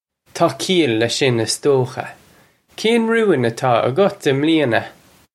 Pronunciation for how to say
Taw kee-ull lesh shin, iss doh-kha. Kayn roo-in ataw uggut im lee-inuh?
This is an approximate phonetic pronunciation of the phrase.
This comes straight from our Bitesize Irish online course of Bitesize lessons.